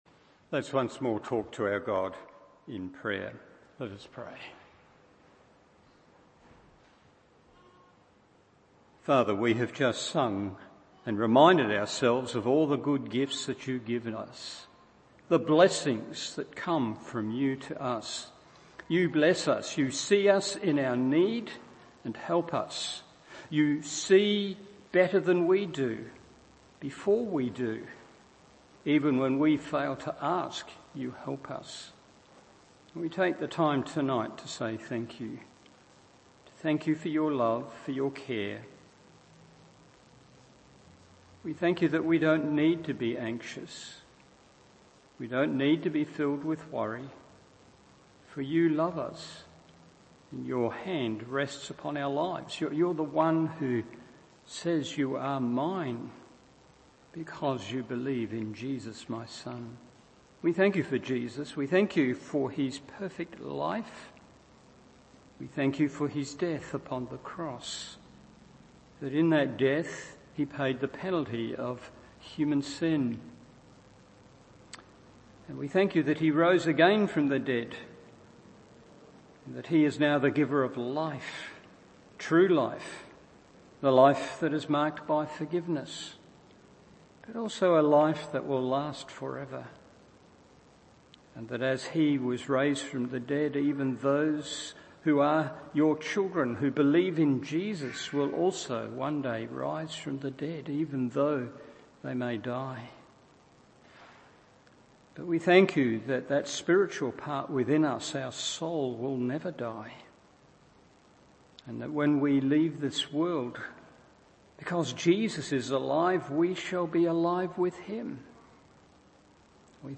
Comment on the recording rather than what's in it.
Evening Service